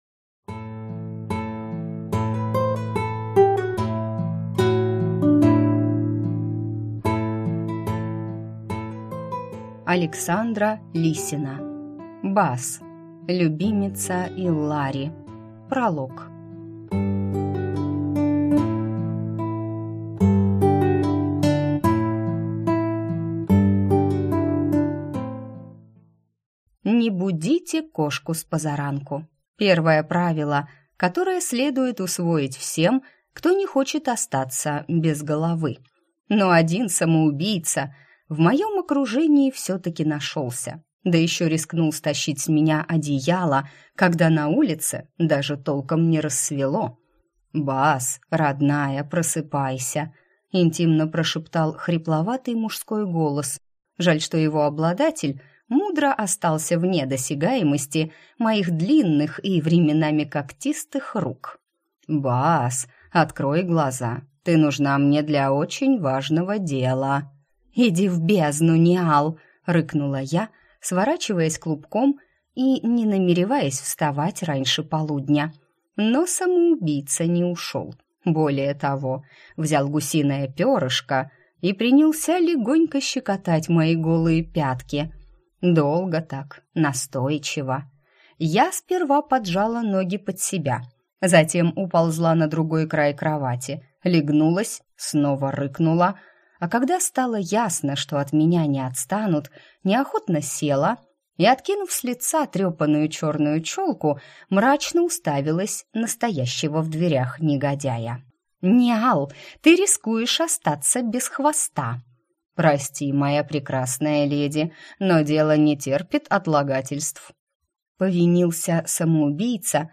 Аудиокнига Бас. Любимица Иллари | Библиотека аудиокниг